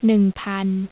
pun; sound like an english pun (joke), bun, gun, fun!